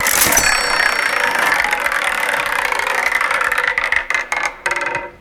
wheel.ogg